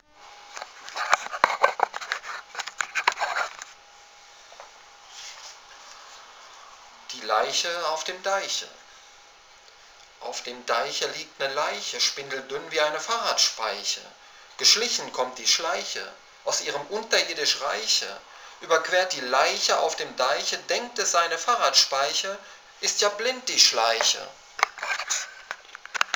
Ich dachte, liest Deinen treuen Homepagegästen mal was vor.